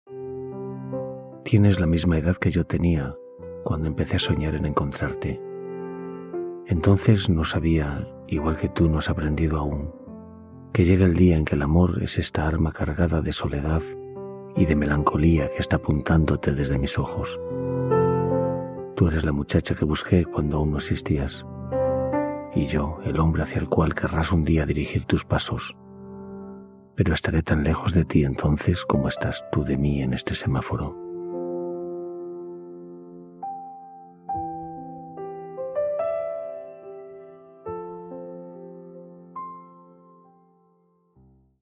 Joan-Margarit.-La-muchacha-del-semafor.-Auphonic_music.mp3